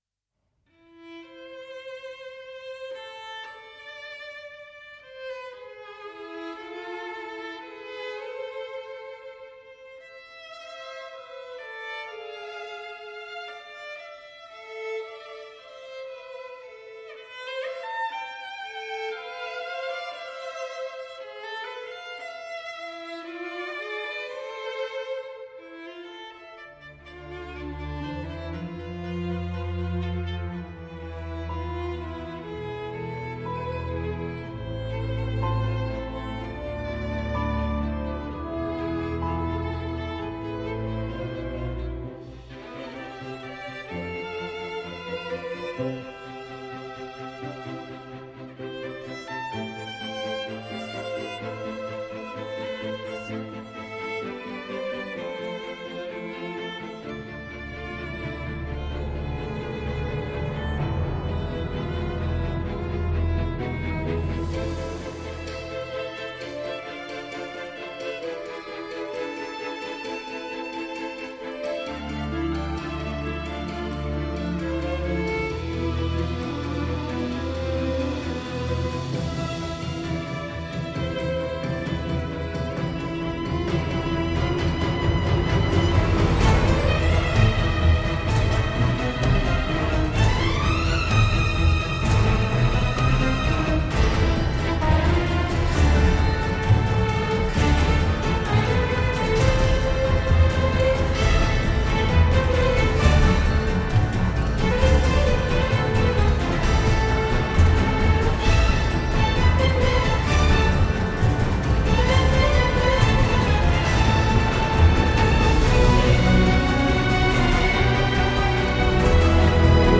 موسیقی سریال
instrumental